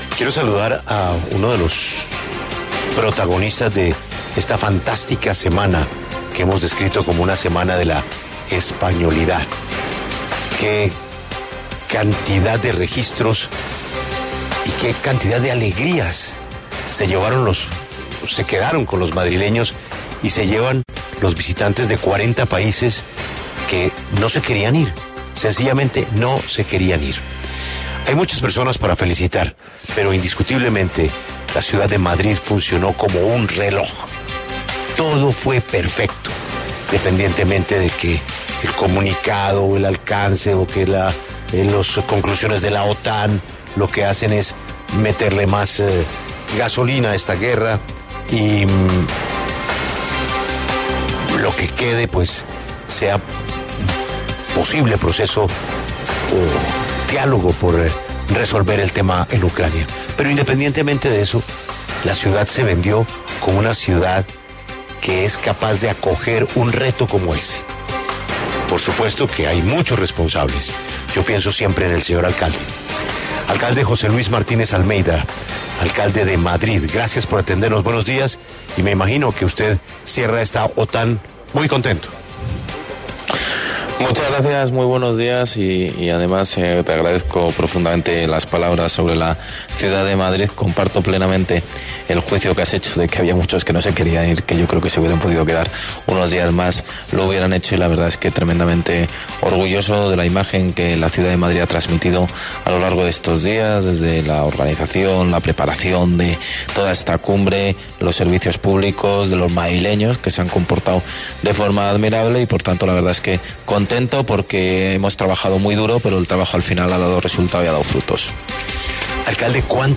José Luis Martínez Almeida, alcalde de Madrid, habló en La W sobre los puntos clave que se trataron en la Cumbre de la OTAN. También insistió en trabajar por la recuperación de la economía de la ciudad.